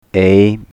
"a" only; "b c" has been cut out, and I have zoomed in on the time scale to show the fluctuations in more detail: